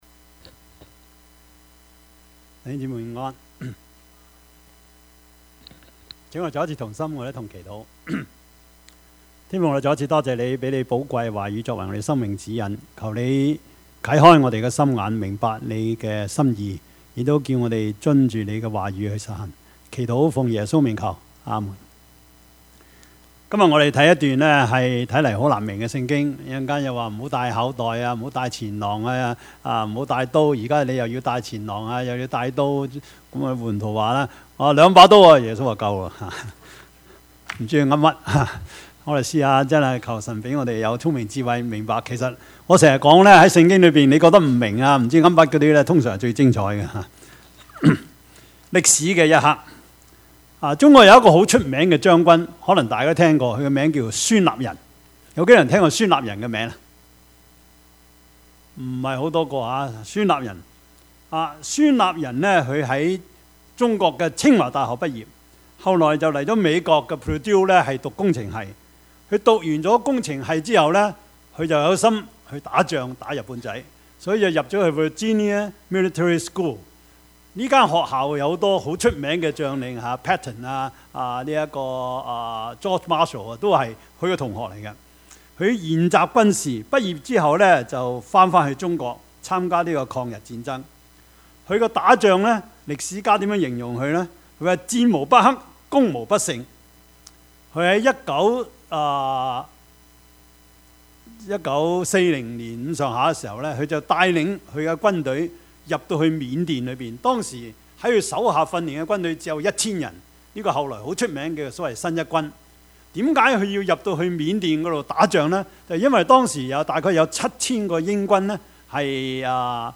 Service Type: 主日崇拜
Topics: 主日證道 « 父父子子 主啊！